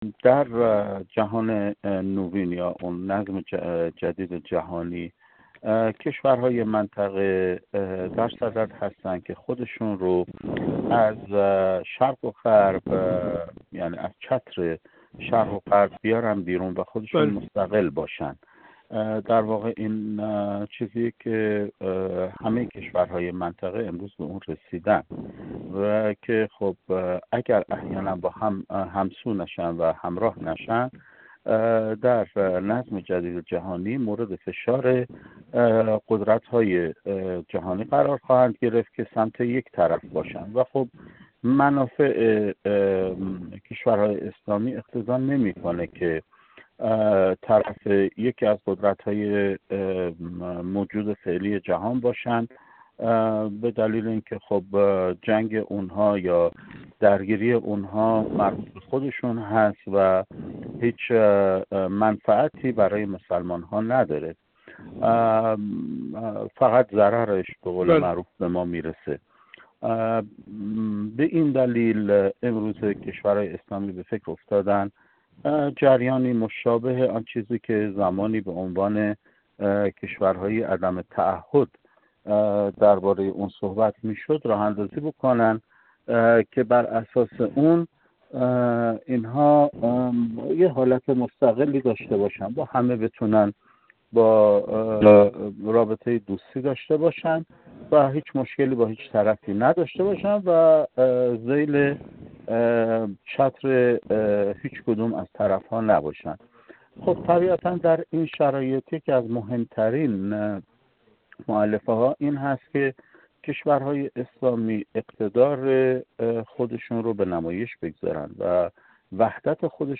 کارشناس و تحلیلگر مسائل منطقه
گفت‌وگو